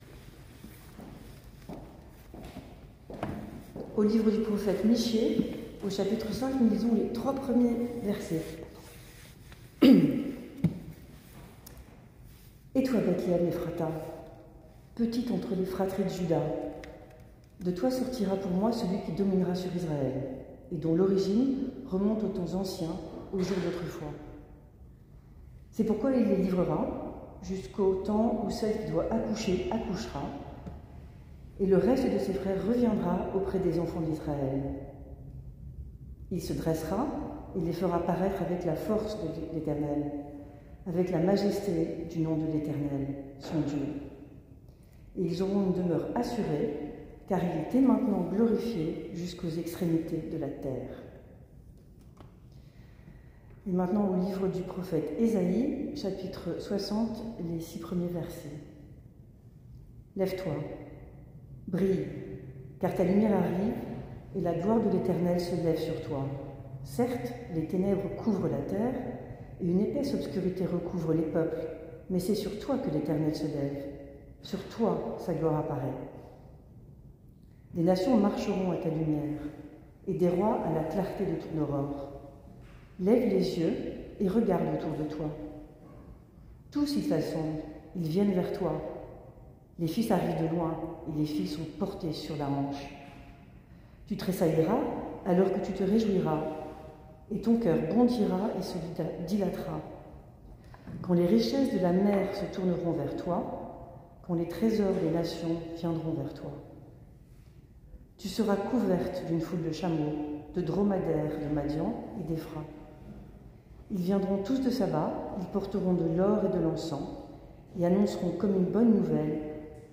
Culte du 08 janvier 2023